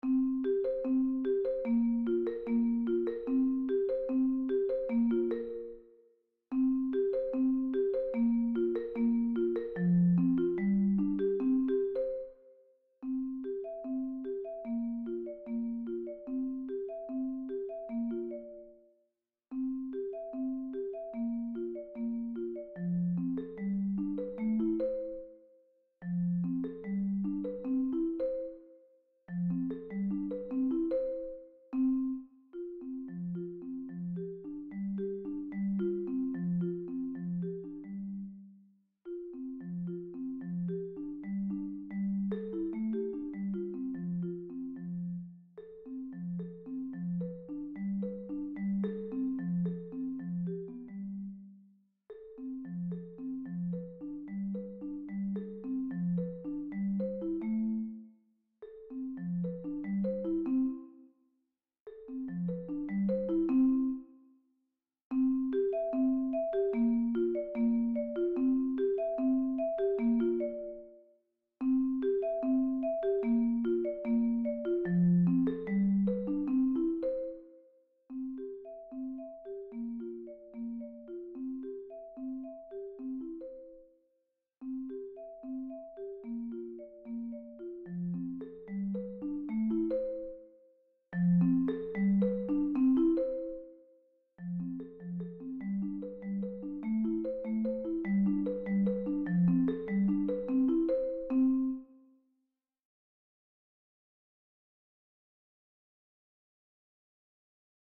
Voicing: Marimba